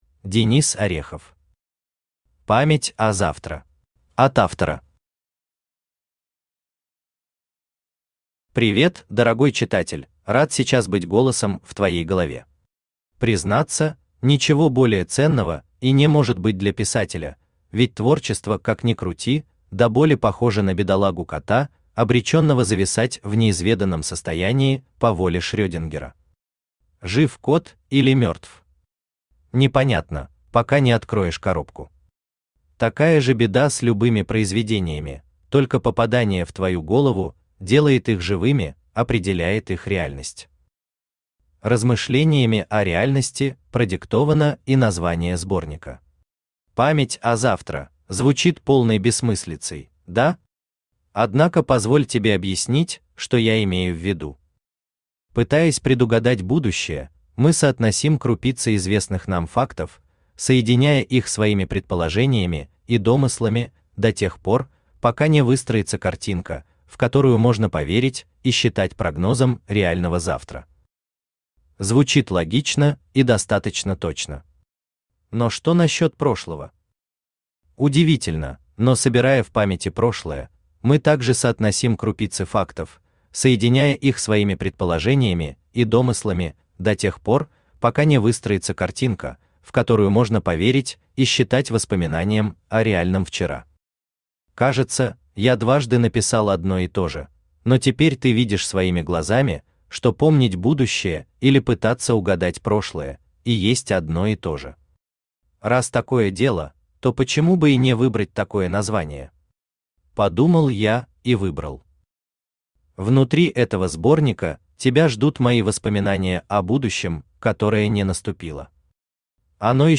Аудиокнига Память о завтра | Библиотека аудиокниг
Читает аудиокнигу Авточтец ЛитРес.